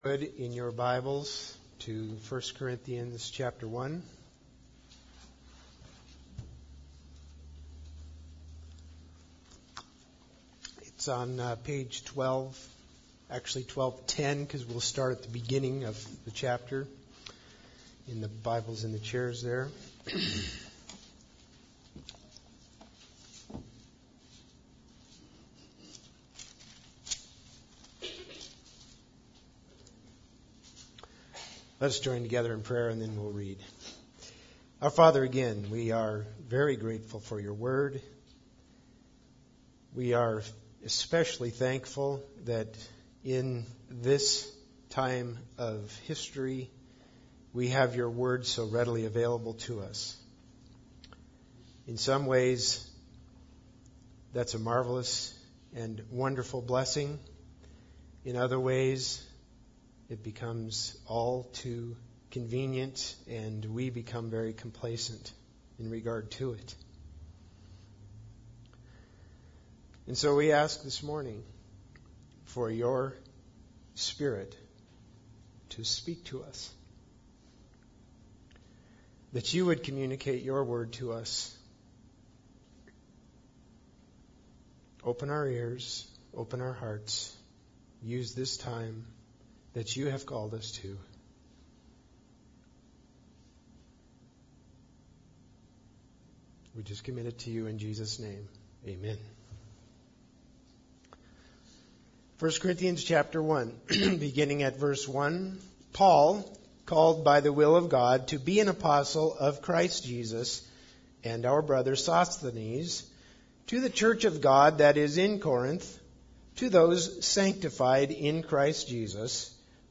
1 Corinthians 1:18-31 Service Type: Sunday Service Bible Text